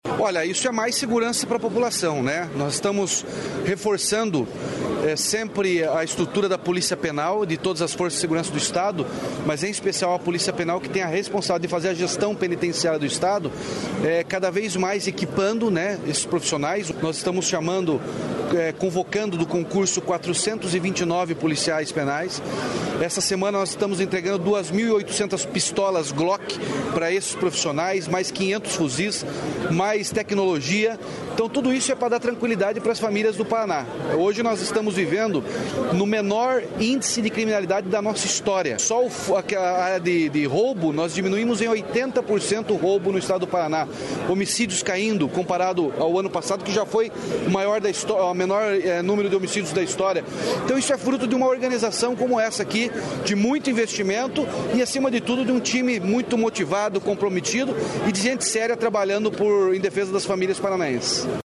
Sonora do governador Ratinho Junior sobre a contratação de policiais penais